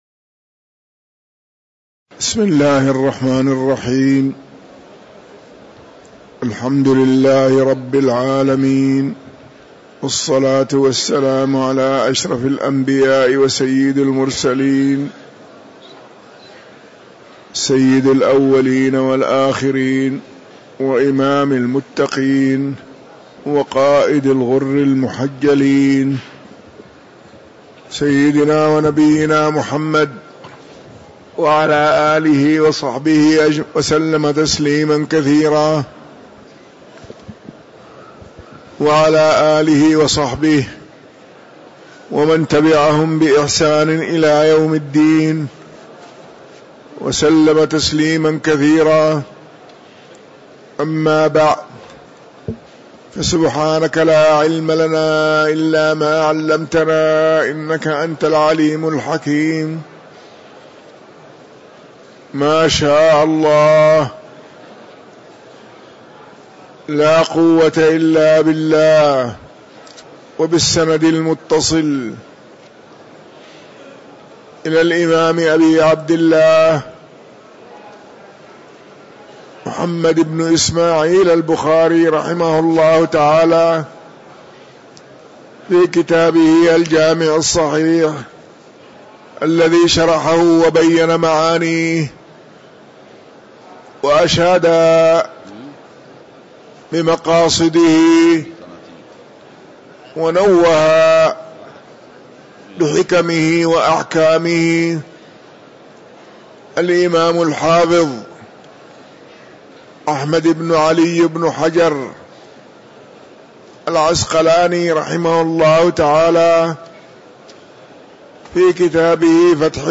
تاريخ النشر ٣ محرم ١٤٤٤ هـ المكان: المسجد النبوي الشيخ